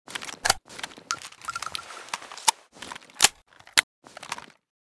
bm16_reload.ogg.bak